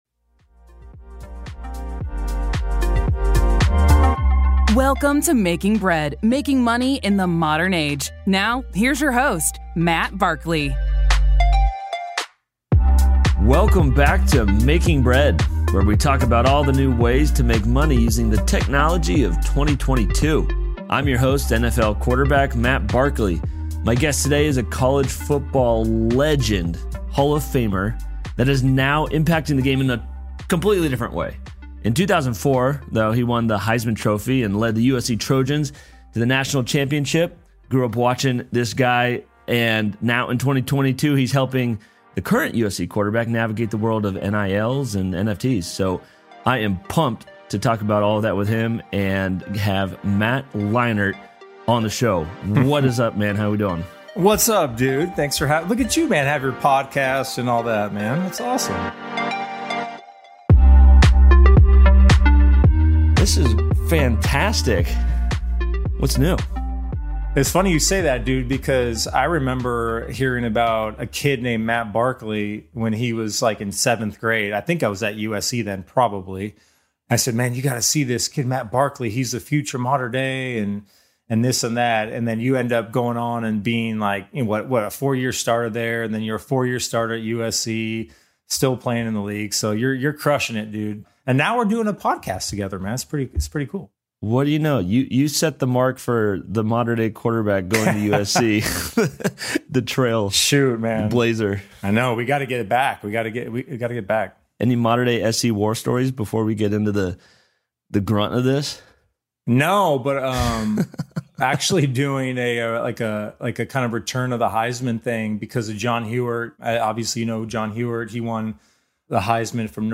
Right now there are more ways than ever to make money through investments, and Making Bread is here to answer your questions. Join NFL player Matt Barkley as he brings you the latest news, interviews, and entertainment in the world of cryptocurrency, NFTs, sports gambling, and more.